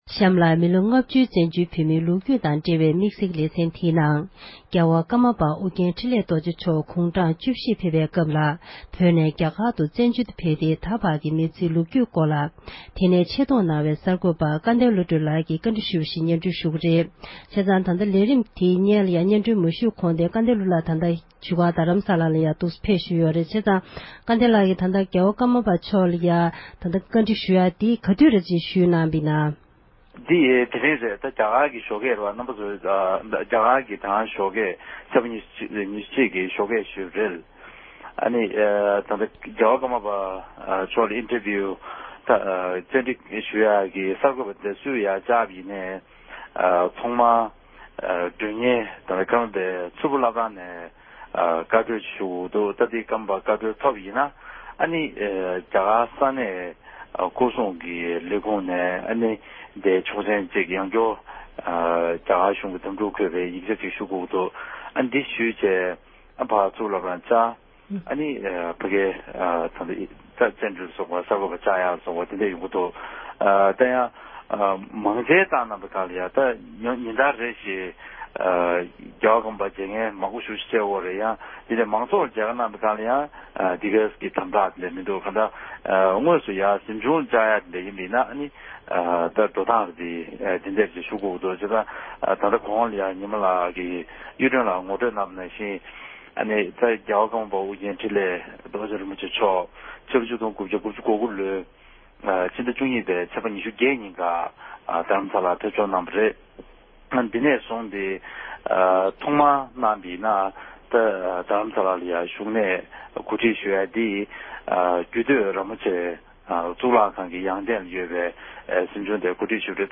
ཀརྨ་པ་མཆོག་ལ་བཅར་འདྲི།